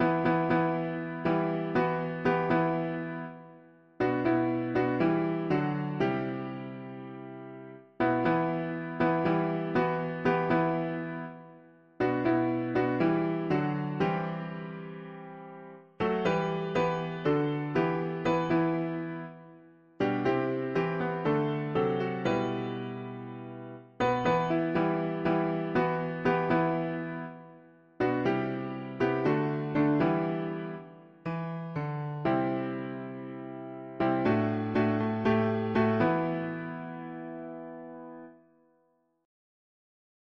Key: E minor